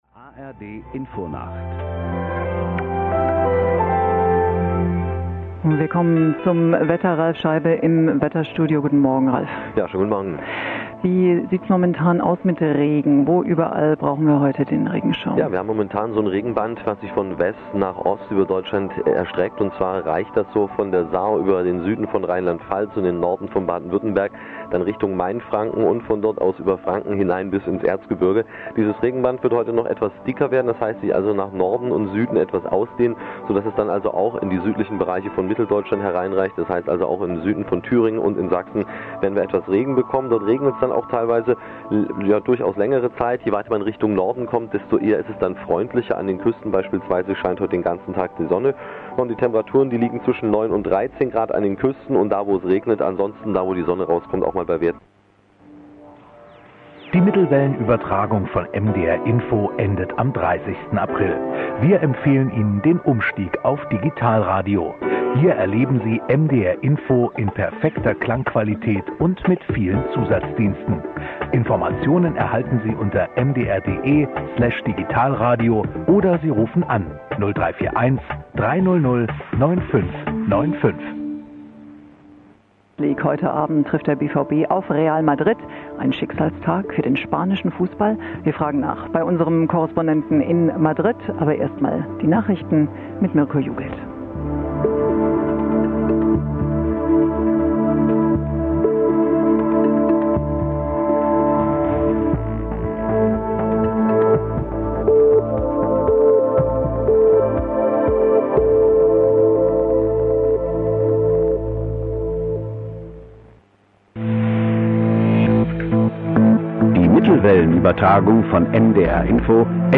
Was: mdr INFO auf Mittelwelle
Wo: Leipzig-Wiederau, 783 kHz; Wilsdruff 1044 kHz; Reichenbach 1188 kHz (Mitschnitt Wiederau)